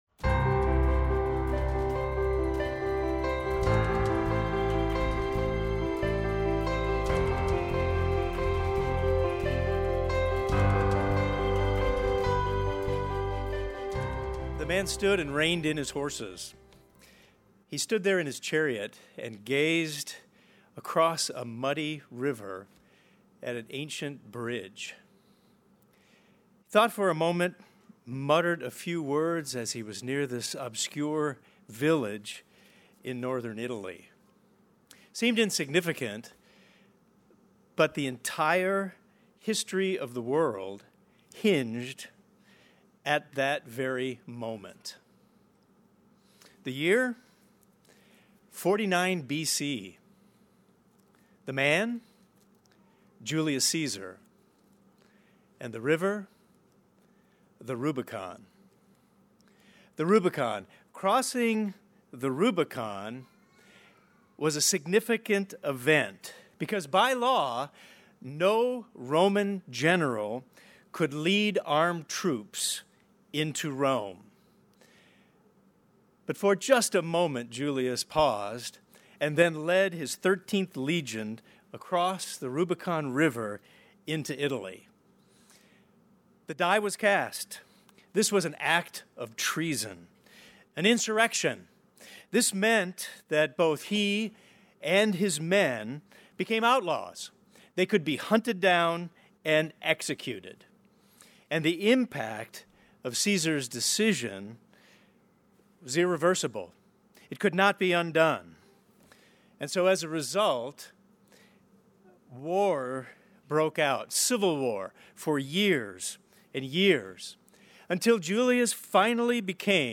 This sermon discusses the state of our culture and how we as God's people should respond.